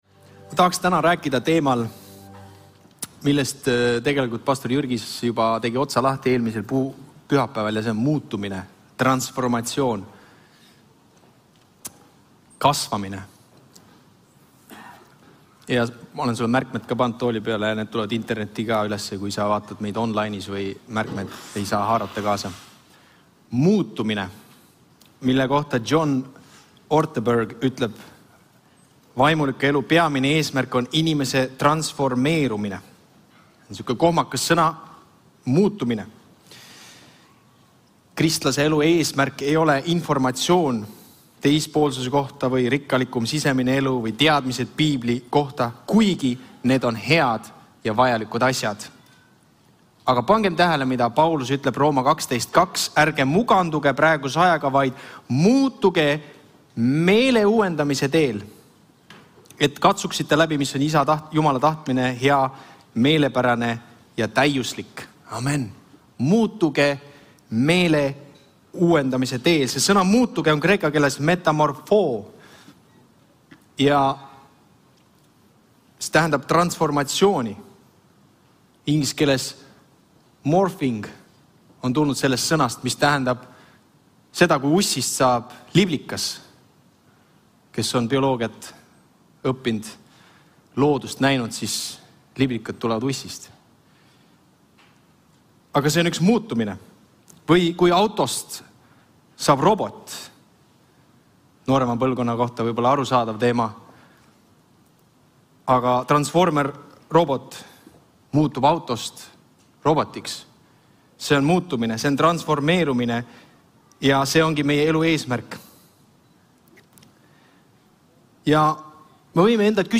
06.04.2025 Jutlus - Meie peame muutuma